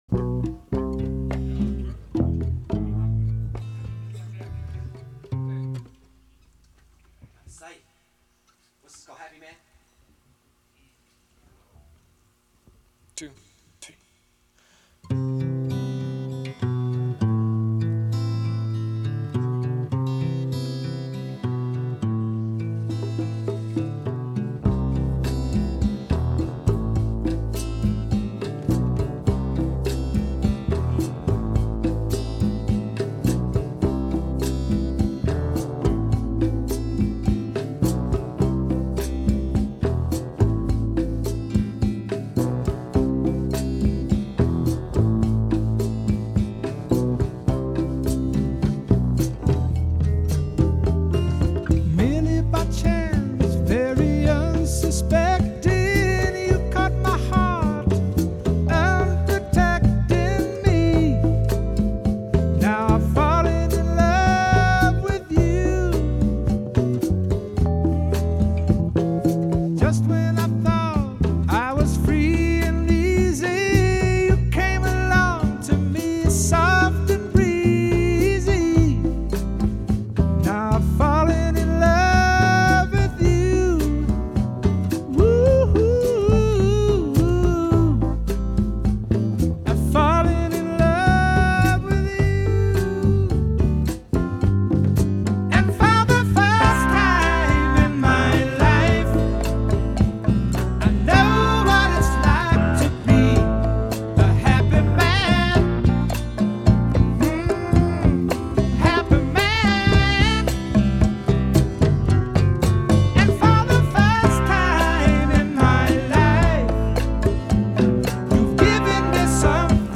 Медляки